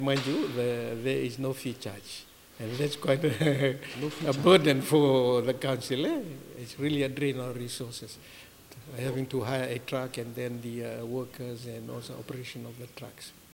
[Tavua Special Administrator Chair Luke Mataiciwa]